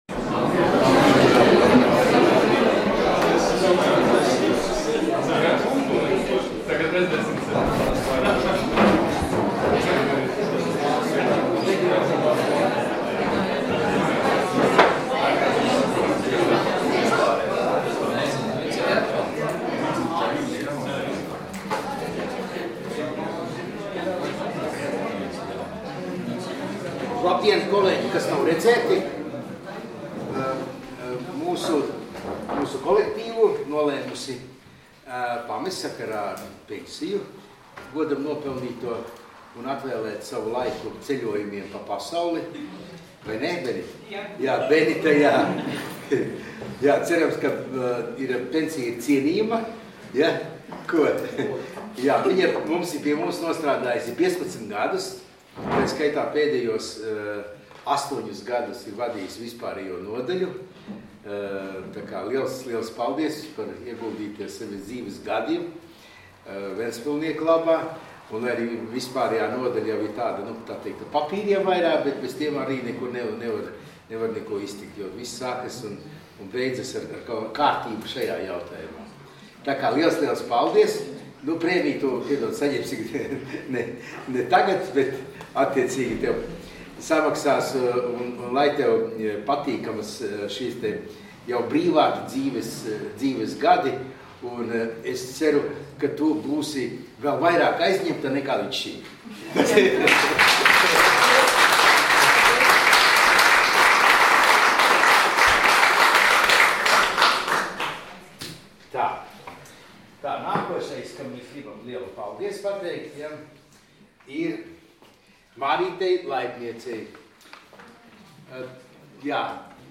Domes sēdes 18.08.2017. audioieraksts